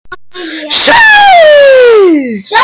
Sheesh Screaming Sound Effect Free Download
Sheesh Screaming